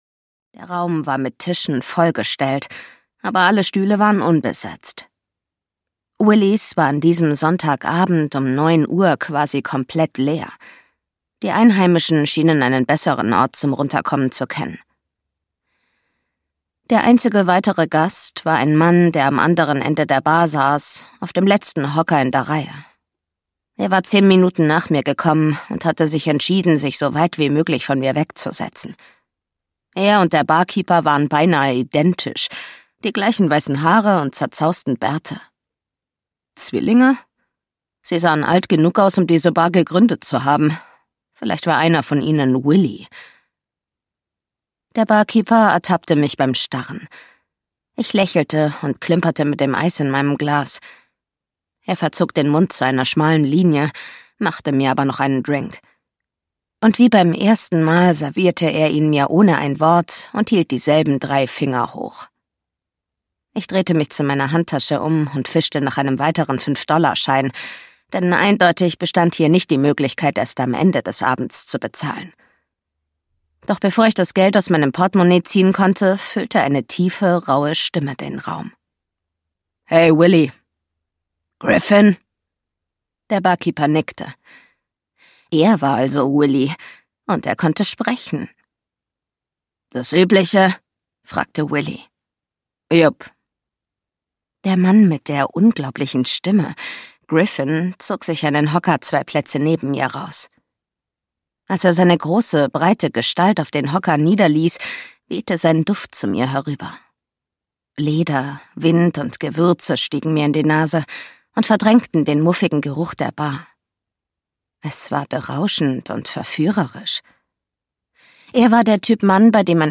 Audio knihaIndigo Ridge (DE)
Ukázka z knihy